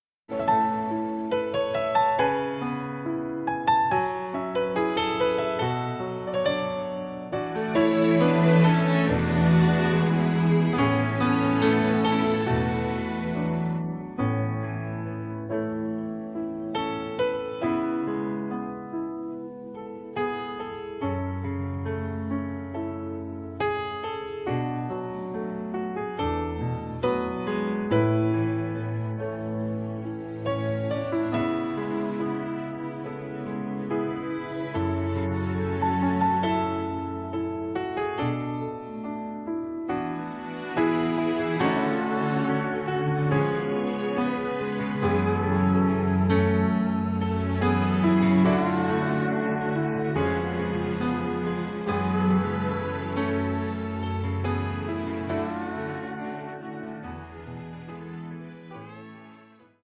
음정 -1키 4:42
장르 가요 구분 Voice Cut